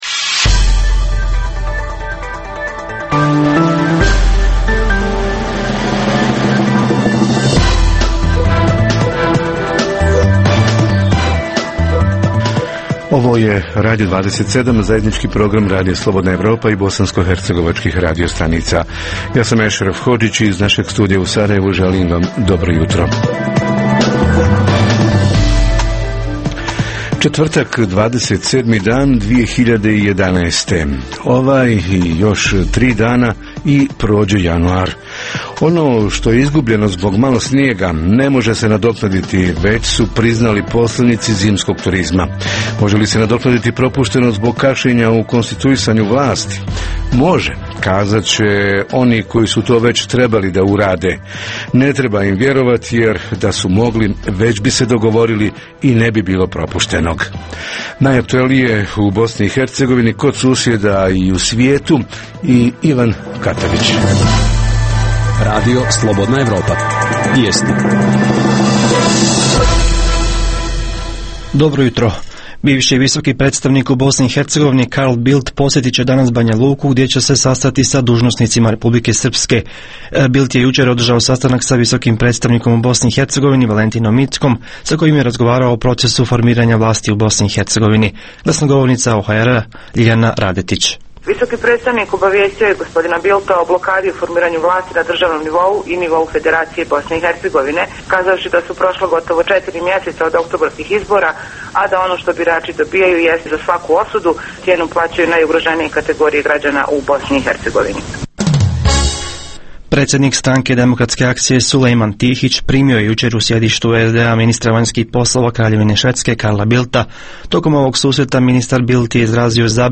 U Jutarnjem programu RSE pokušavamo naći odgovor na pitanje: kako i koliko trpi lokalna zajednica zbog kašnjenja u konstituisanju državne, entitetske i kantonalne vlasti? Reporteri iz cijele BiH javljaju o najaktuelnijim događajima u njihovim sredinama.
Redovni sadržaji jutarnjeg programa za BiH su i vijesti i muzika.